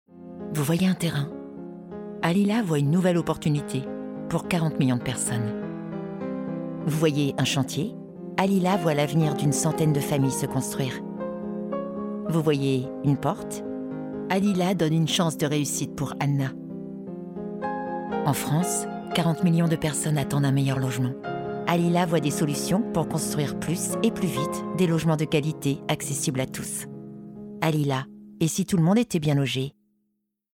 concernée